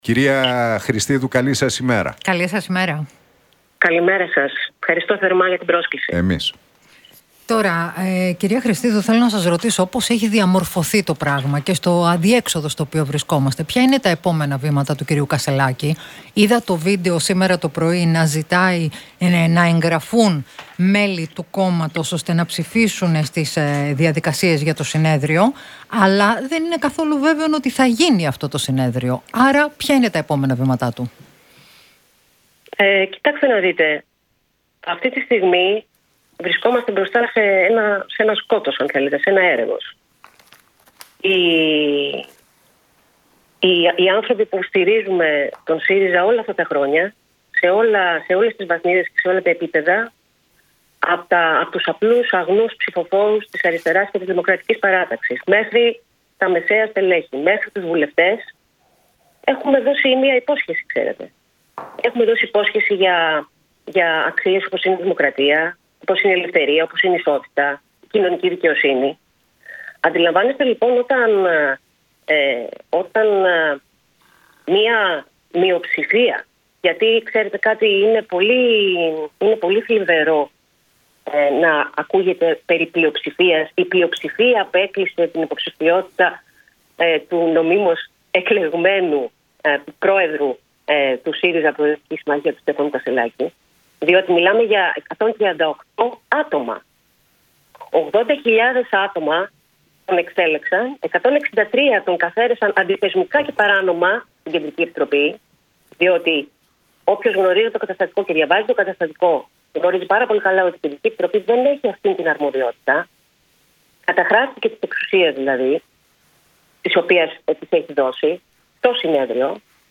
Χρηστίδου στον Realfm 97,8: Μέχρι η ελιτίστικη δήθεν αριστερή νομενκλατούρα να μας διαγράψει όλους, δεν θα φύγει κανείς